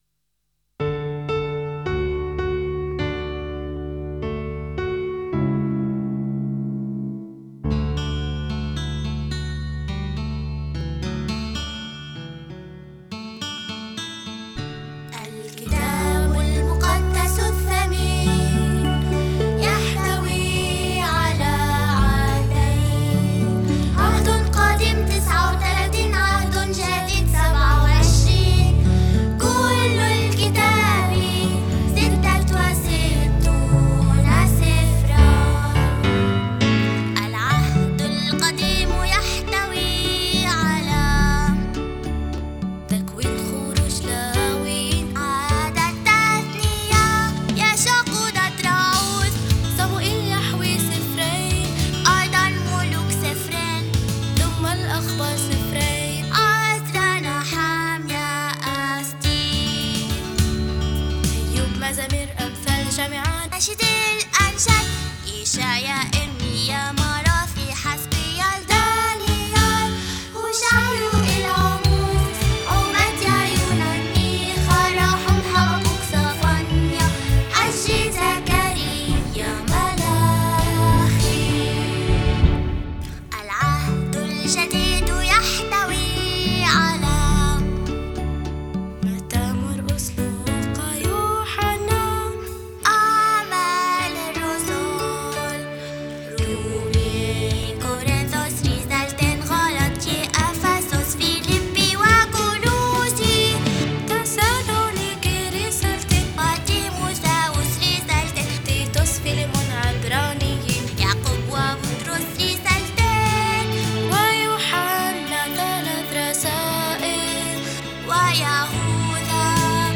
الهدف: ترنيمة تساعد على حفظ أسفار الكتاب المقدس